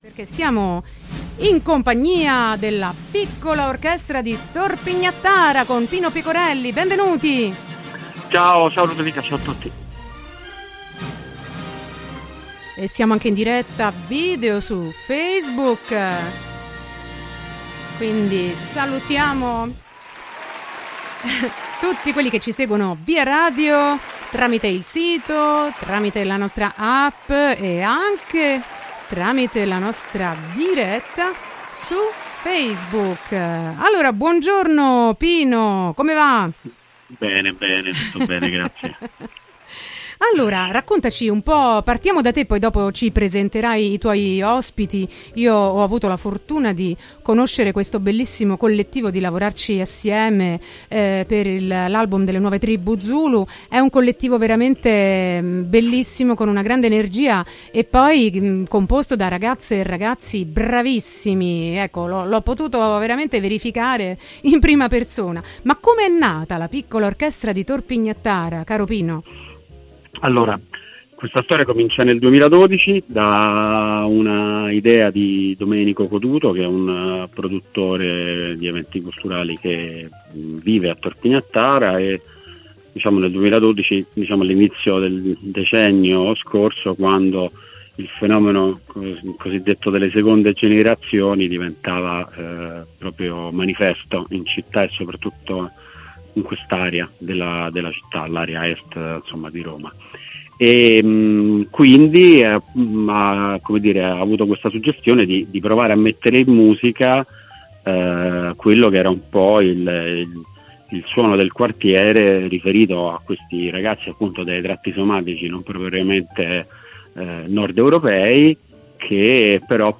L'arcobaleno musicale della Piccola Orchestra di Tor Pignattara: intervista